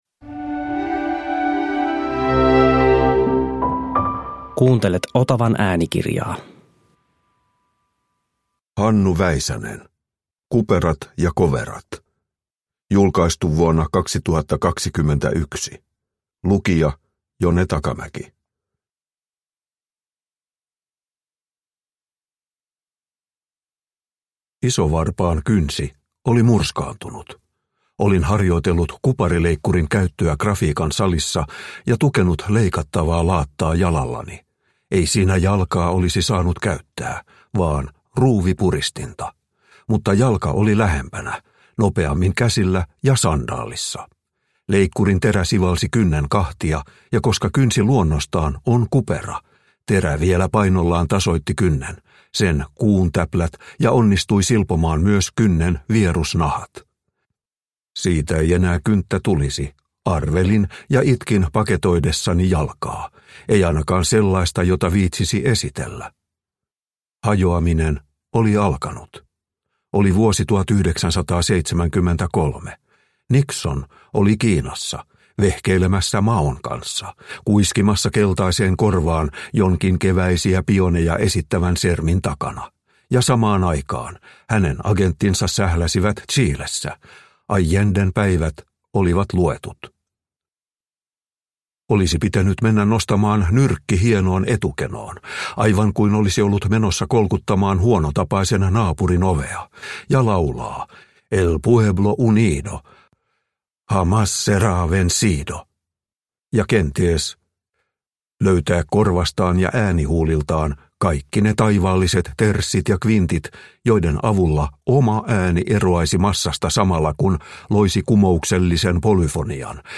Kuperat ja koverat – Ljudbok – Laddas ner